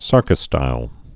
(särkə-stīl)